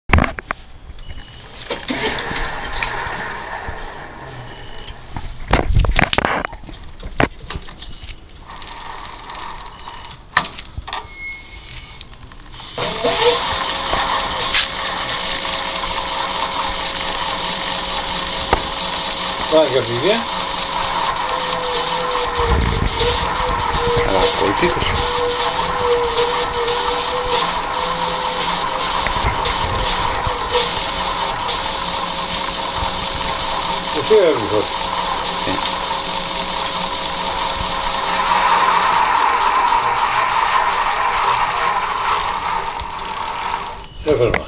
ciao posso chiederti se mi potresti dare una ascoltatina ad un clipaudio? si tratta di una mini registrazione di qualche secondo, riguardante il rumore della motoruota della bici di mio papà, che è una xideko, e volevo capire se era una motorruota gearless o di una geared! se non ho capito male la gearless, è senza ingranaggi, e la geared invece li ha?